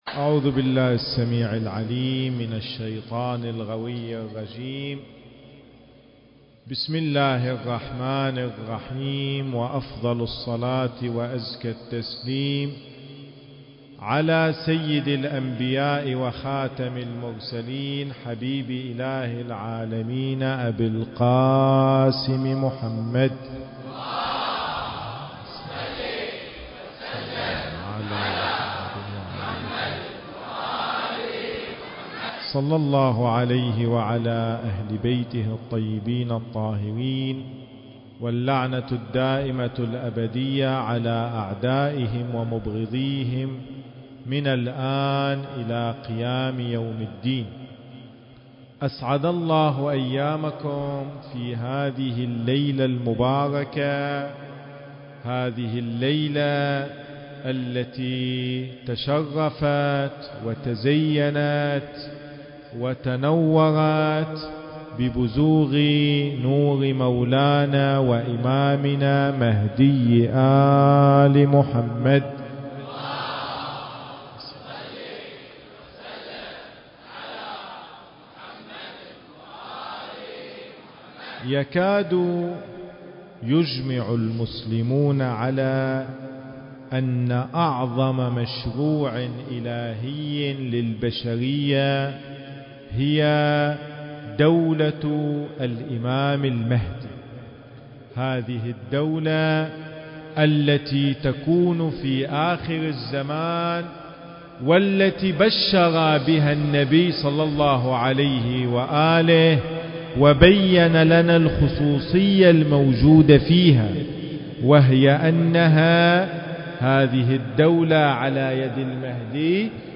المكان: حسينية المصطفى (صلّى الله عليه وآله وسلم) - قرية المطيرفي